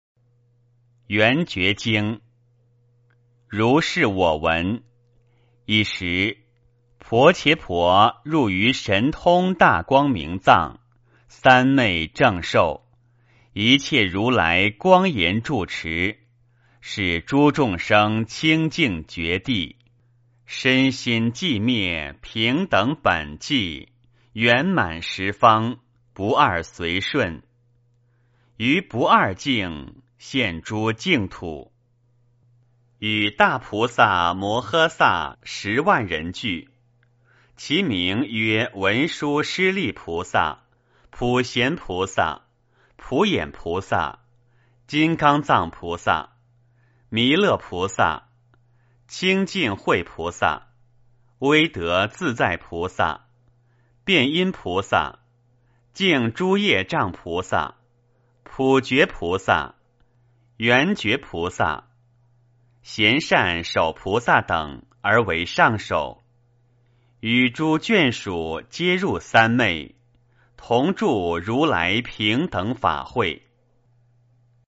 圆觉经-00序分 - 诵经 - 云佛论坛